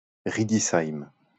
Riedisheim (French pronunciation: [ʁidisajm]